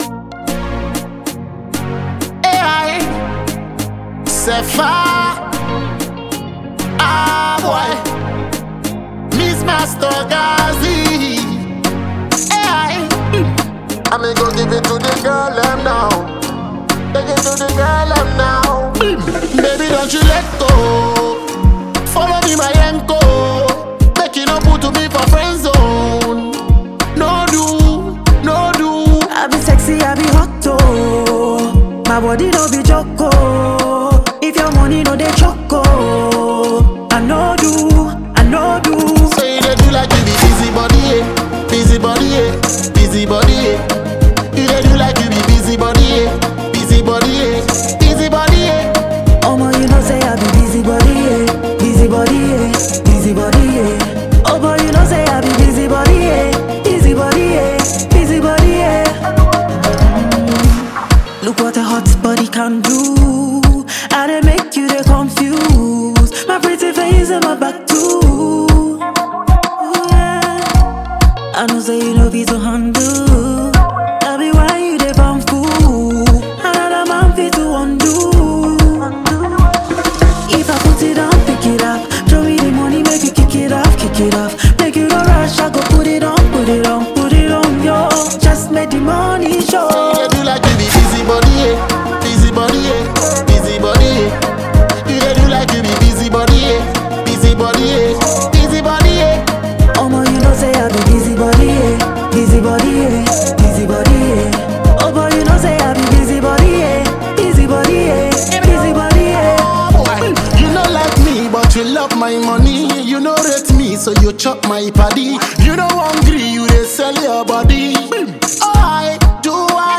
Ghanaian female singer